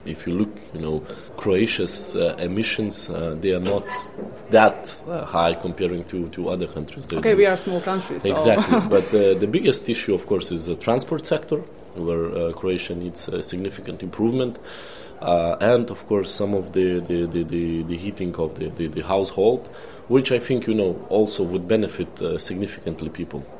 Jedan od govornika na jučerašnjem otvaranju prve Konferencije ovog tipa u Zagrebu bio je, između ostalih, povjerenik Europske komisije za okoliš, oceane i ribarstvo Virginijus Sinkevičius koji je tim povodom gostovao u Intervjuu Media servisa.